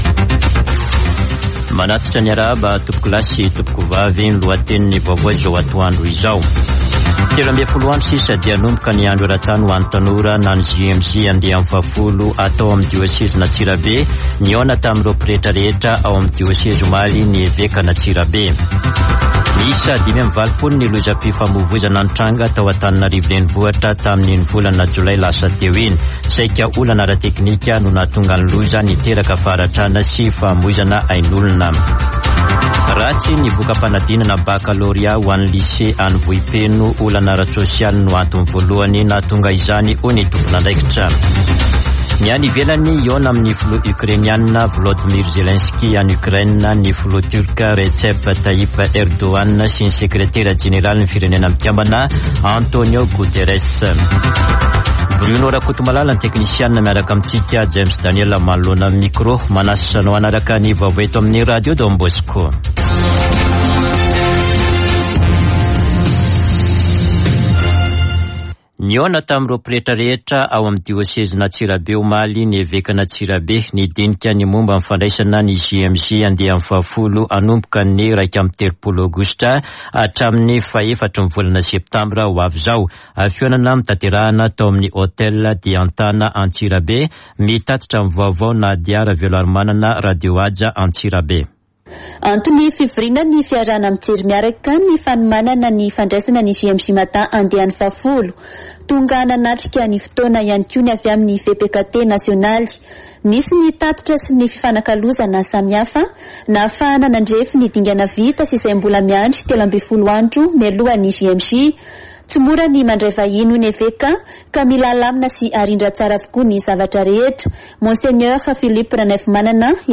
[Vaovao antoandro] Alakamisy 18 aogositra 2022